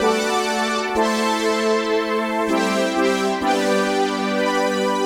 AM_VictorPad_95-A.wav